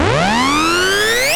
VEC3 Scratching FX